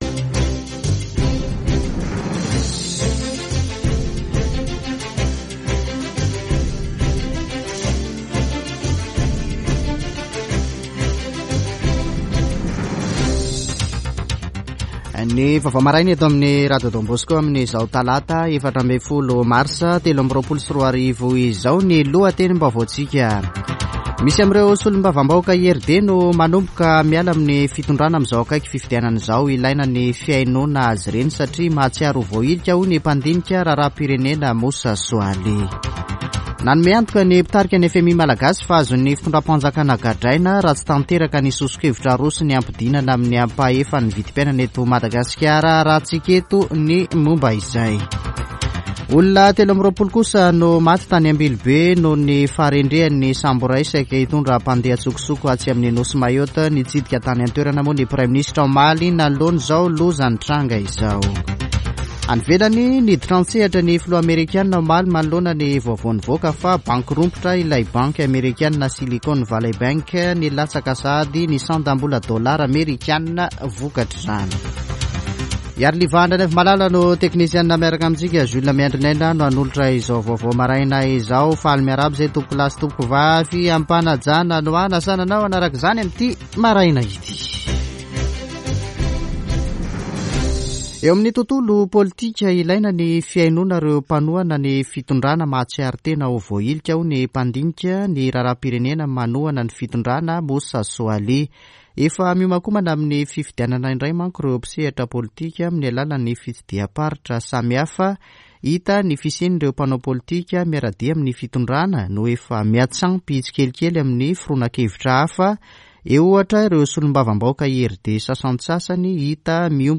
[Vaovao maraina] Talata 14 marsa 2023